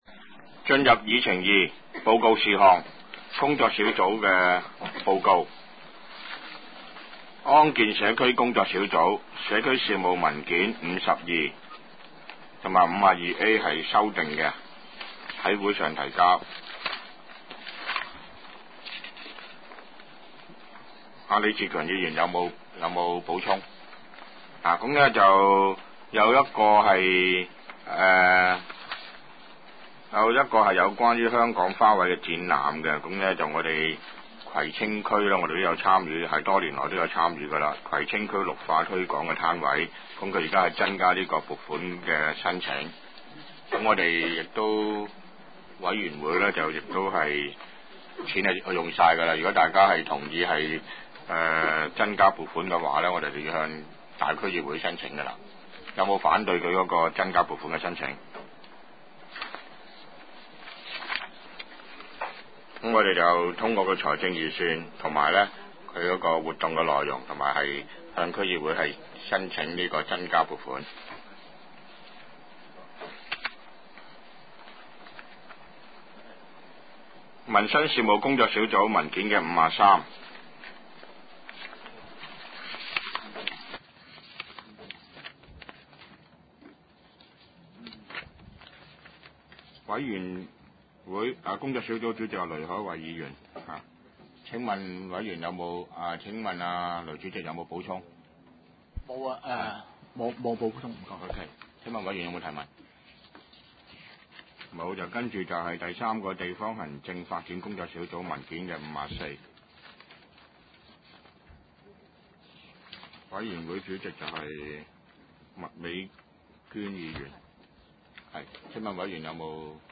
第五次會議(一零/一一)
葵青民政事務處會議室